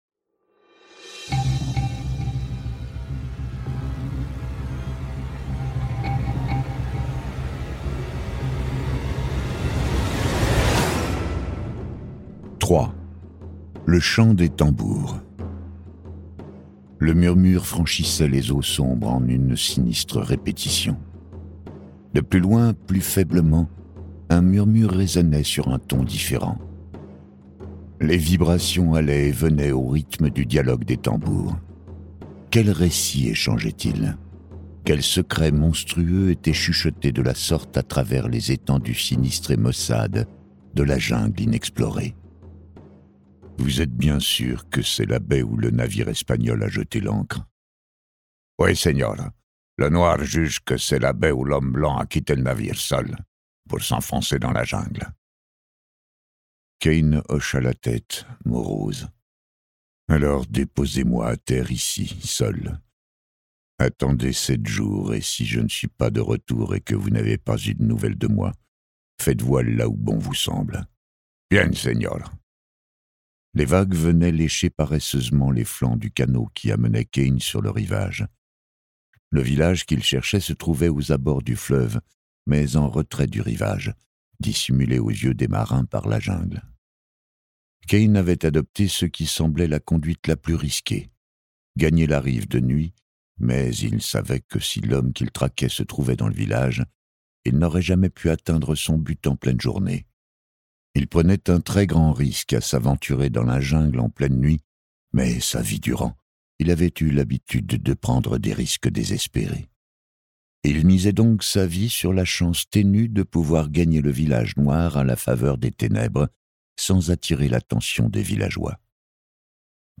Un ouvrage absolument exceptionnel.Ce livre audio est interprété par une voix humaine, dans le respect des engagements d'Hardigan.